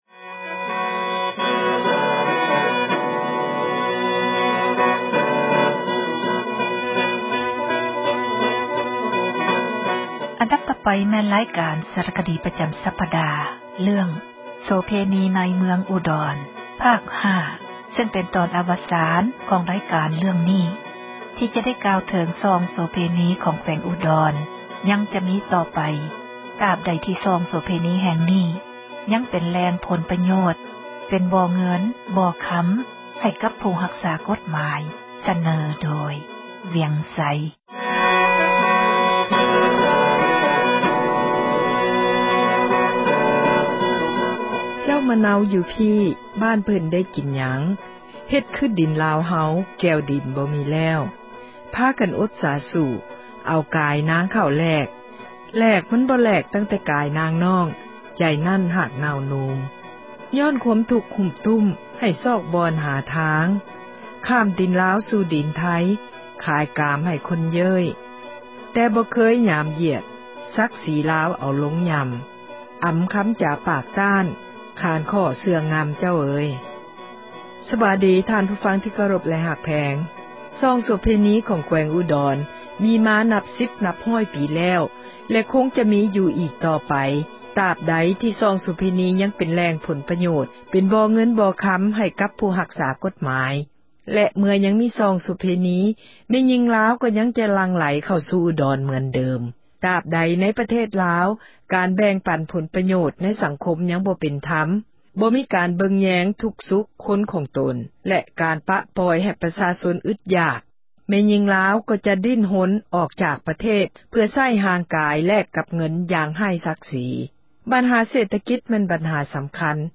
ສາຣະຄະດີ ປະຈຳ ສັປດາ ”ໂສເພນີ ໃນເມືອງອູດອນ” ຕອນ ອາວະສານ...